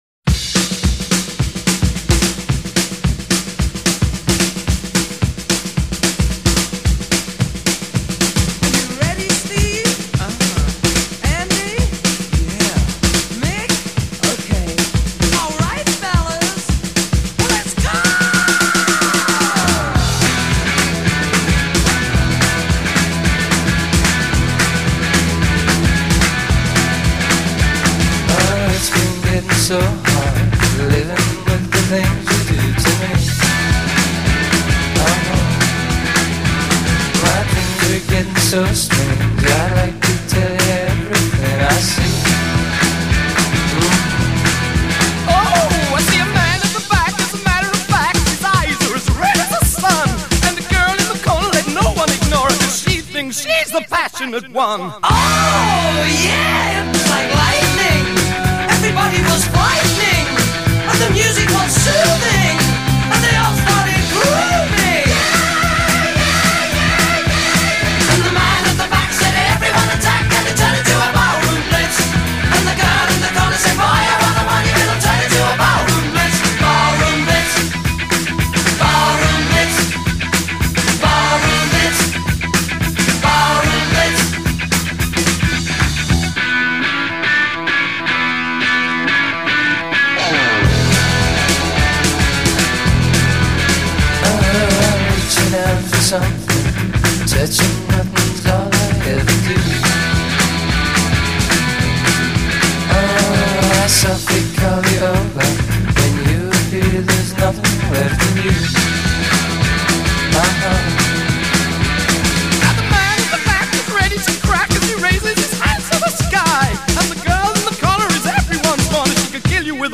британская рок-группа